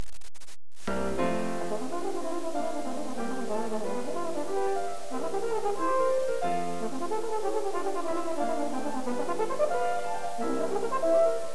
If, like me you relish the challenge of a hard horn piece, but have exhausted all the repertoire given to you,or you are just stuck for something to play, this list of pieces should offer something fresh and new for you to try:
This is one of the more virtuosic pieces that the repertoire has to offer. It basically has three sections: The first section is a theme and variations, the second is the more 'cantabile' section, and the last is the most virtuosic part of the piece, combining fast triplets with arpeggios leading up to high notes, to make it one of the more difficult pieces in the repertoire too!